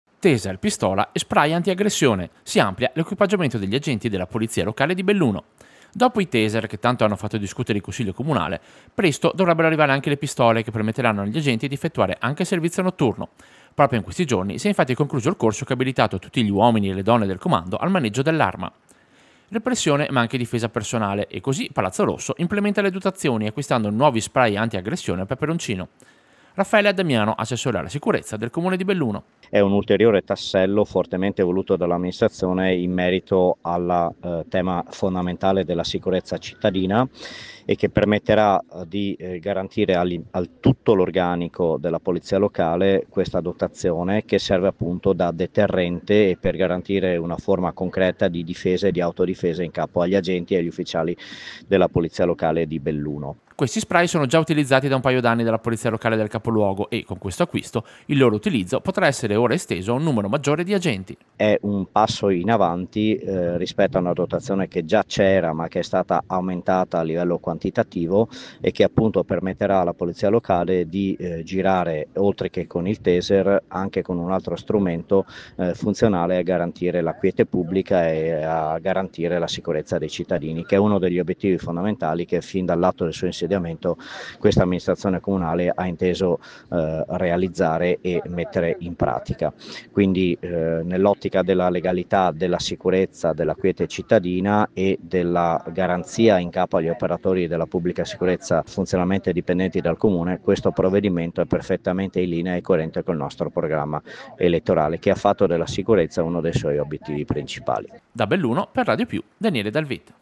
Servizio-Nuovi-equipaggiamenti-vigili-Belluno.mp3